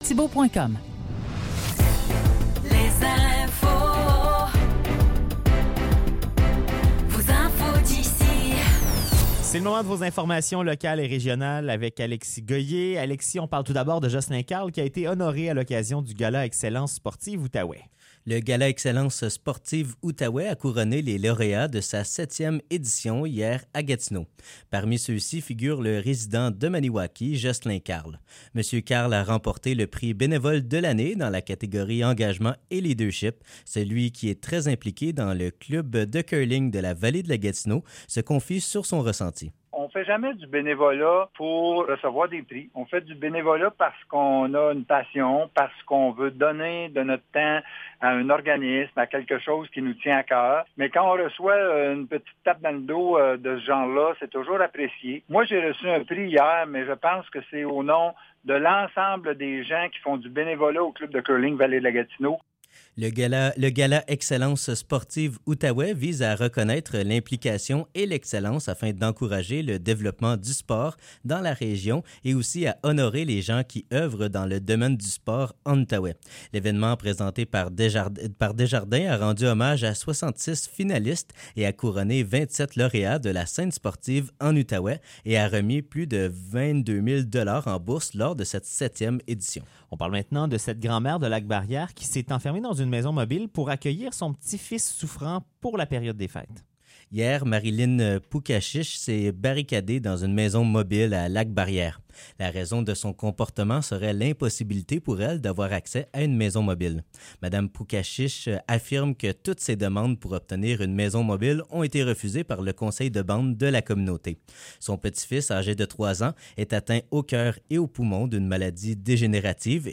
Nouvelles locales - 22 décembre 2023 - 16 h